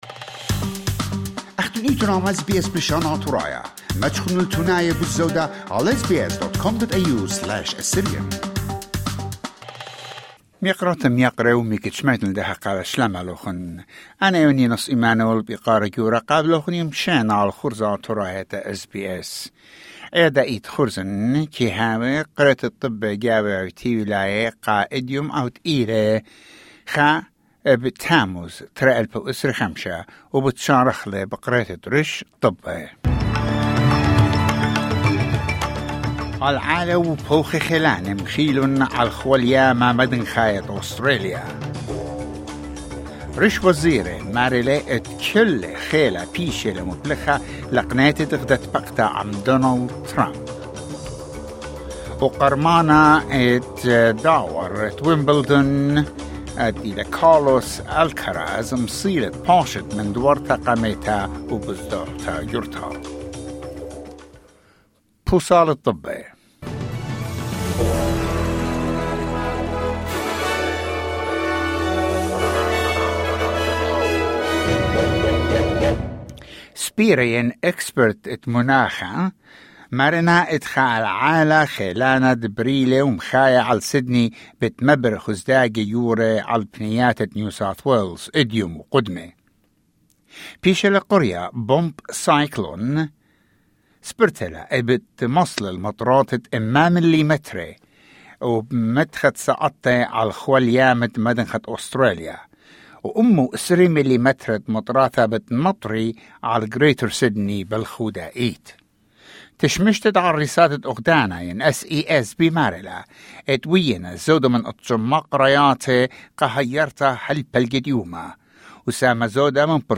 SBS Assyrian news bulletin: 1 July 2025